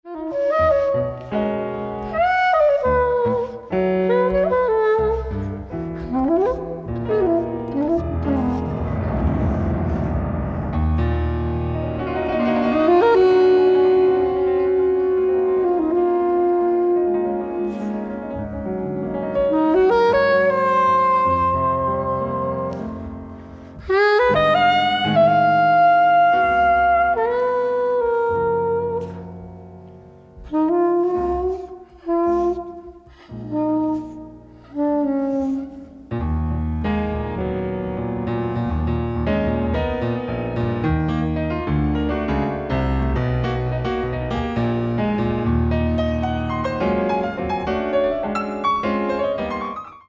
LIVE AT ILLINGEN, GERMANY
SOUNDBOARD RECORDING